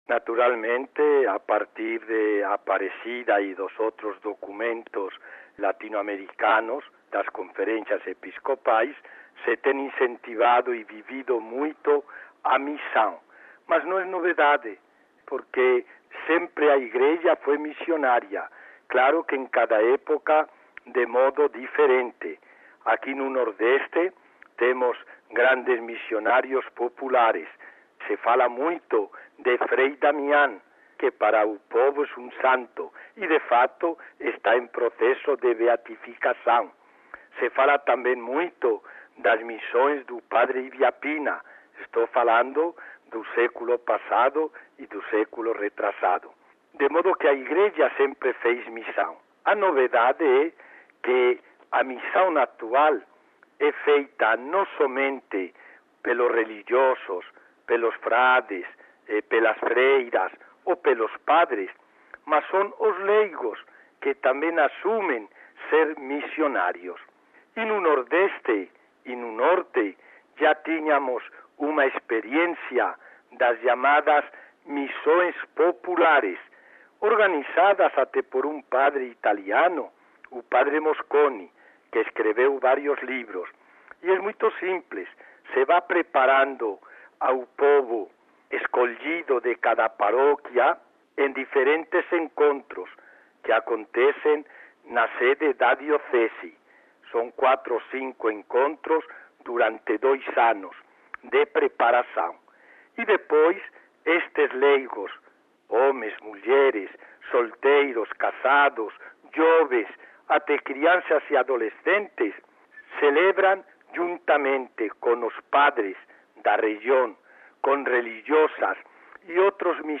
Cidade do Vaticano (RV) - Amigo ouvinte, o quadro "O Brasil na Missão Continental" de hoje traz a participação do bispo da Diocese de Cajazeiras - PB, Dom José González Alonso.